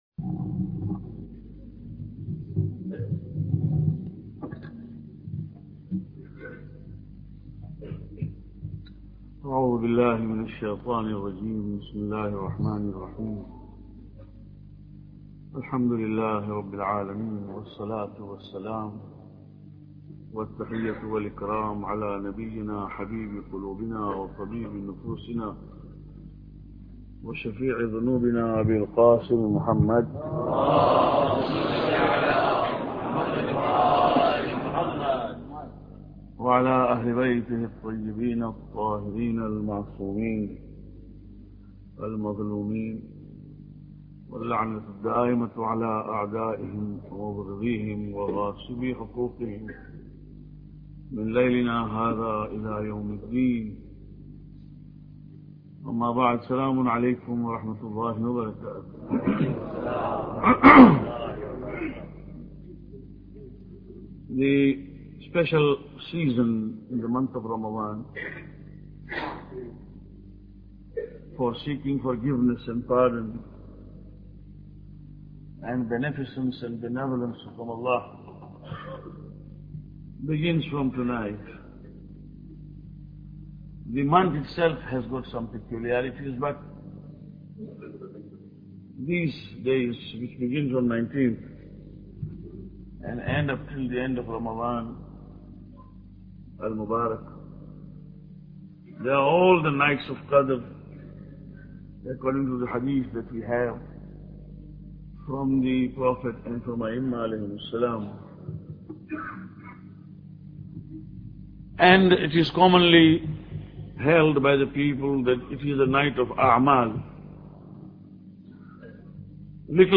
Lecture 16